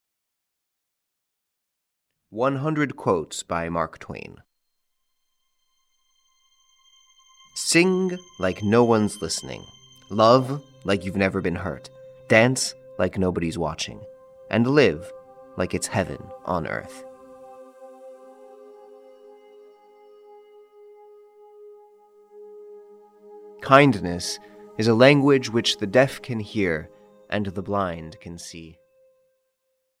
100 Quotes by Mark Twain (EN) audiokniha
Ukázka z knihy